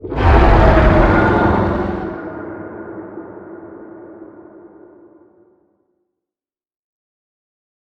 Sfx_creature_hiddencroc_callout_01.ogg